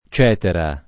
cetra [©%tra o ©$tra] s. f. («strumento musicale») — antiq. o poet. cetera [©%tera o